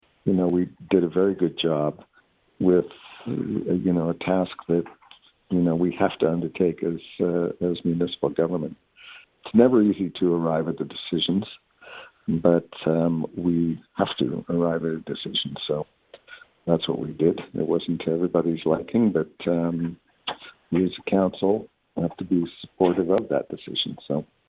Mayor Ferguson says it’s never easy to arrive at these decisions but a decision has to be made.